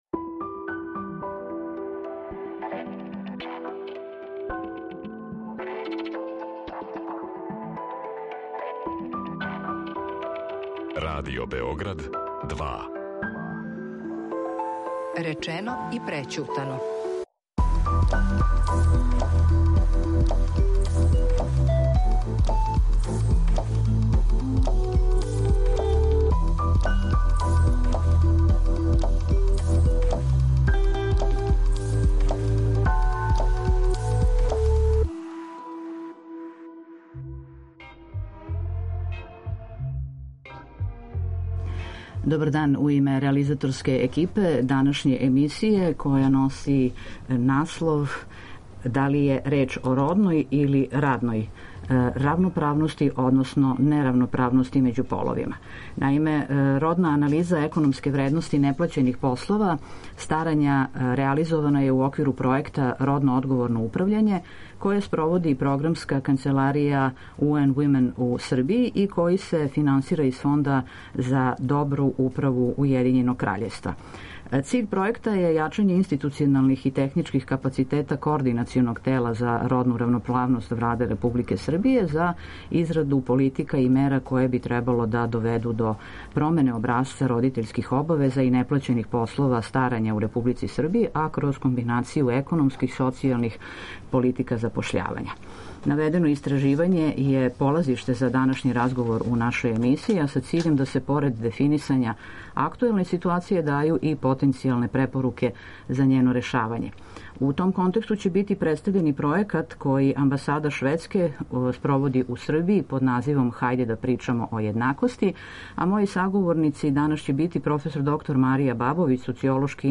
Наведено истраживање је полазиште за данашњи разговор у емисији, а циљ је да се ‒ поред дефинисања актуелне ситуације ‒ дају и потенцијалне препоруке за њено решавање.